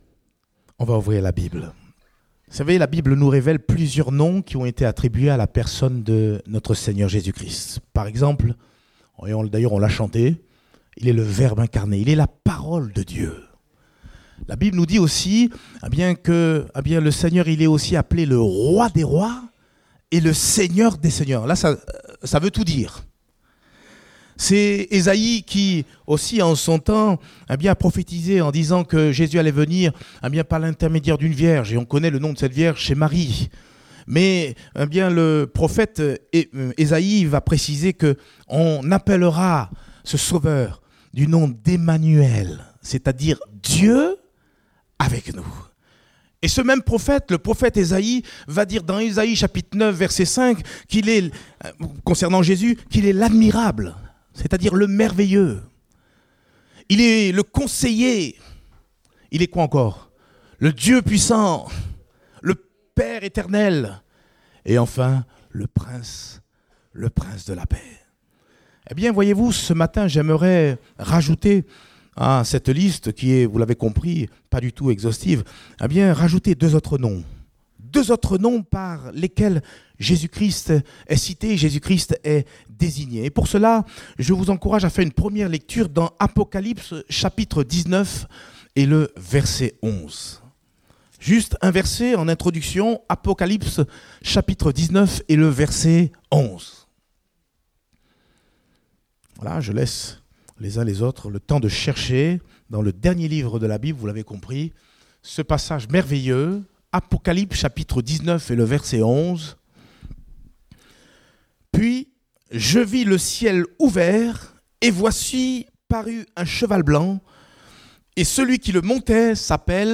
Date : 6 janvier 2019 (Culte Dominical)